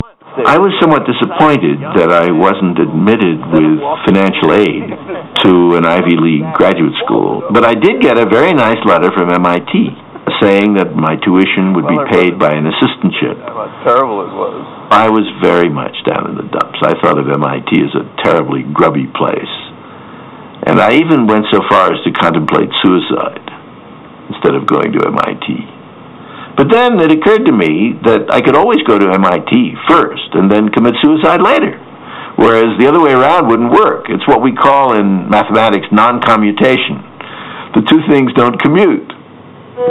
Some years ago I taped a program.
I remember distinctly an interview with Murray Gell-Mann, 1969 Nobel Prize winning physicist, talking about when he applied to graduate schools. There was one bit I liked so much that I played it, and recorded it with my Psion 5mx.